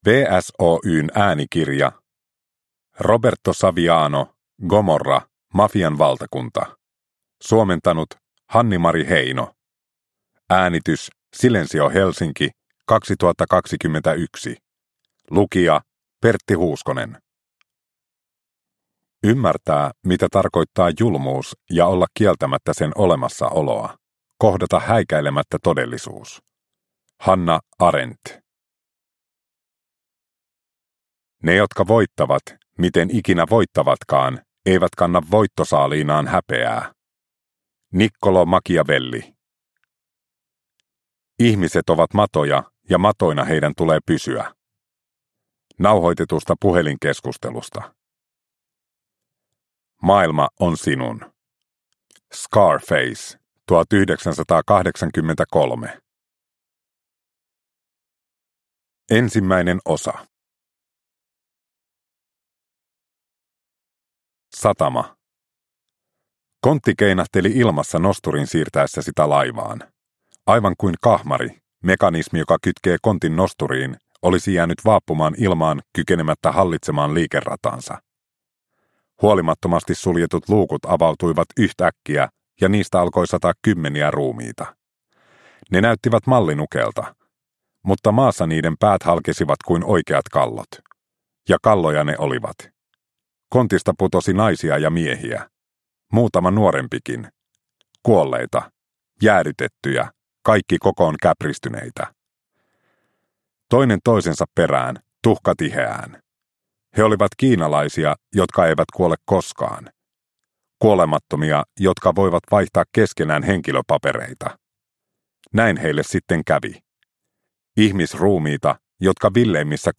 Gomorra. Mafian valtakunta – Ljudbok – Laddas ner